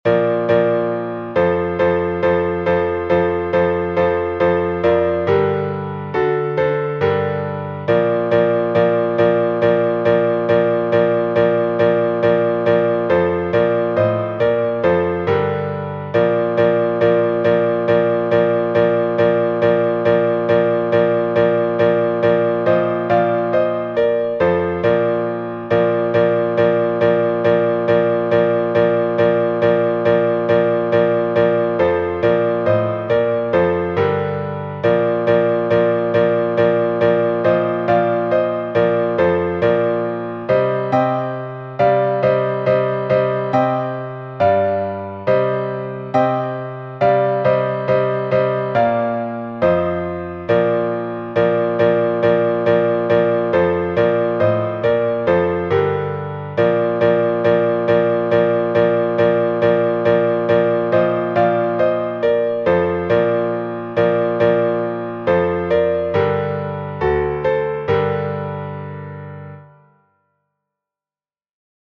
напев Оптиной пустыни